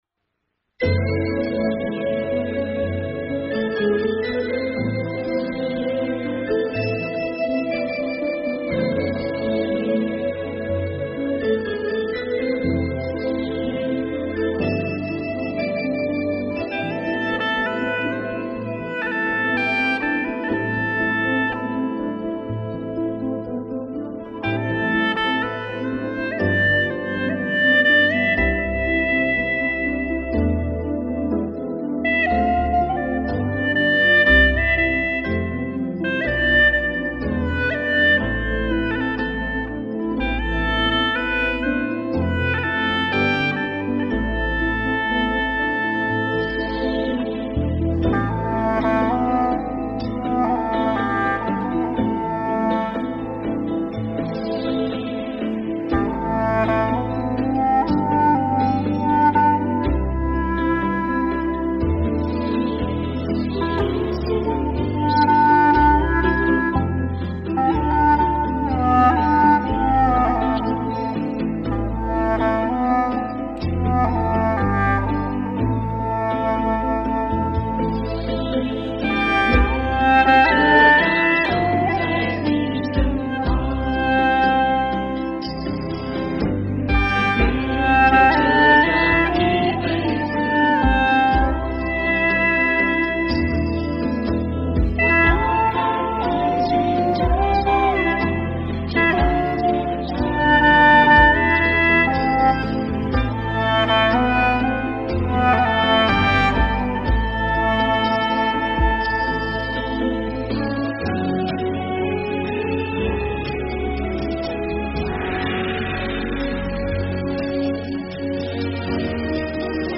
调式 : C 曲类 : 流行